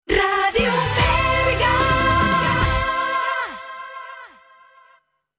Dos indicatius amb la identificació de la ràdio.